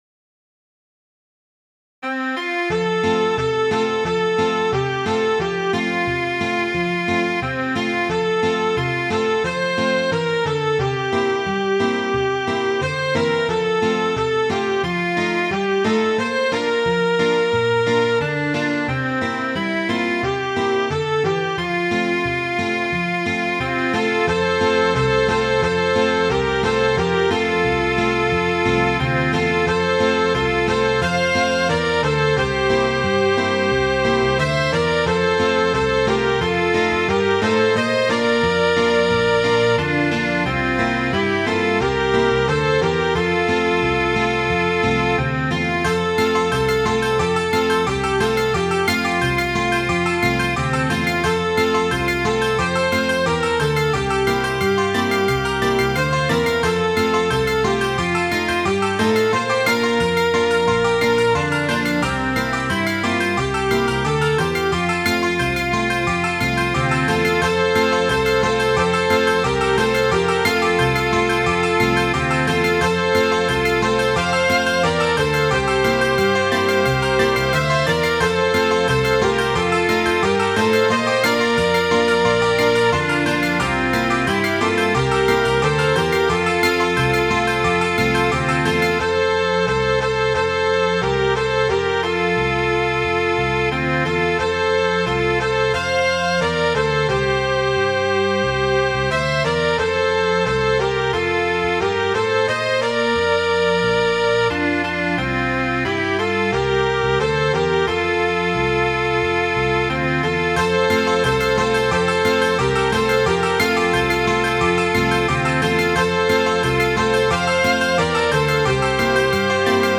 Midi File, Lyrics and Information to Red River Valley